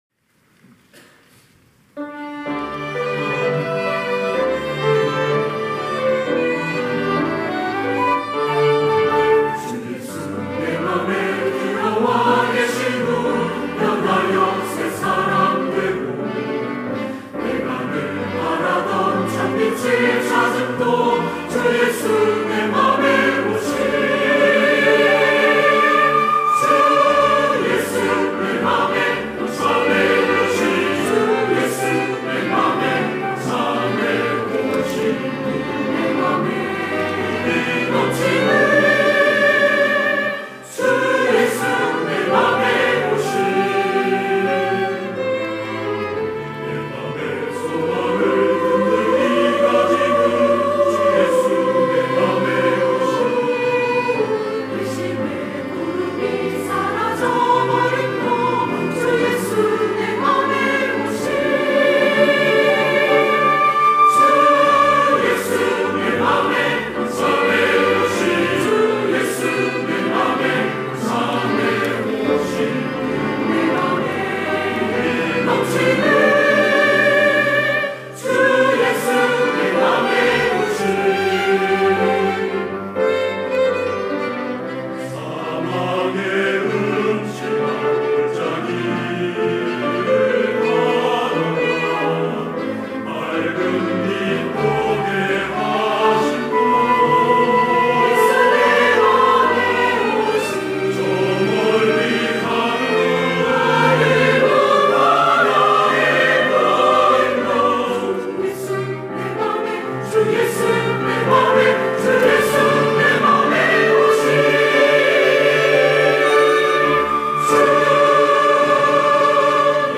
할렐루야(주일2부) - 주 예수 내 맘에 오심
찬양대